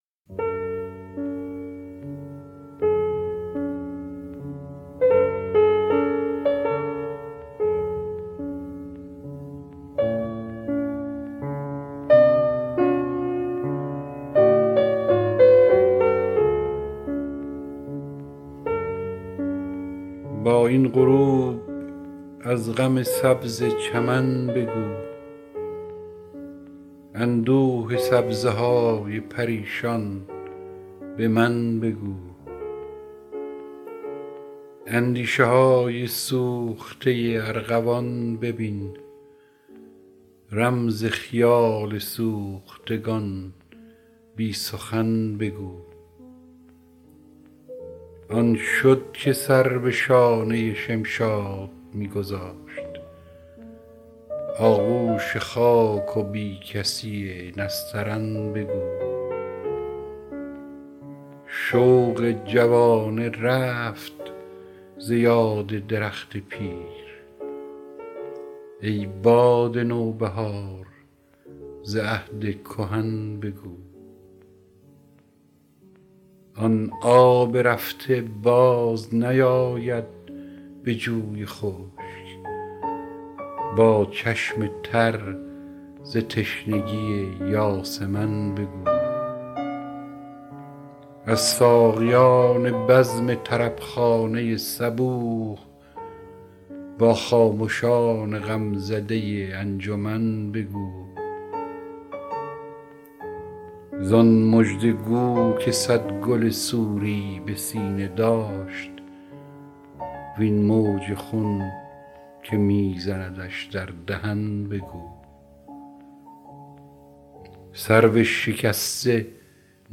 دانلود دکلمه غروب چمن با صدای هوشنگ ابتهاج
گوینده :   [هوشنگ ابتهاج]